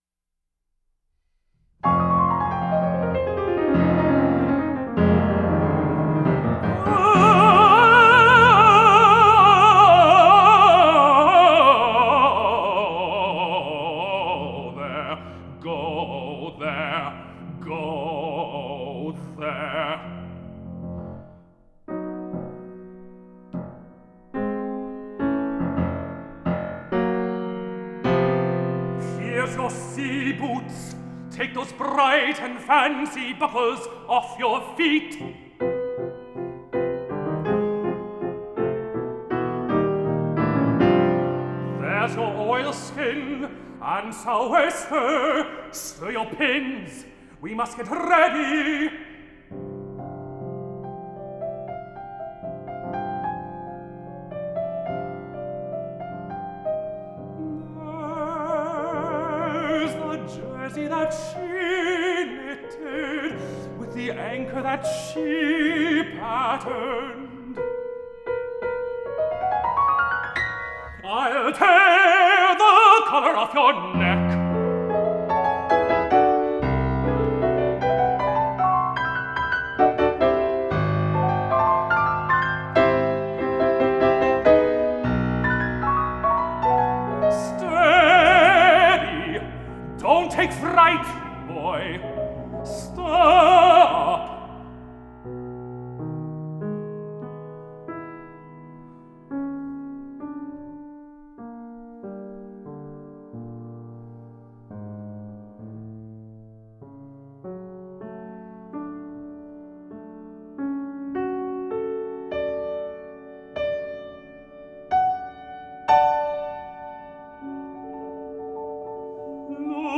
tenor
piano 6:20 Go There!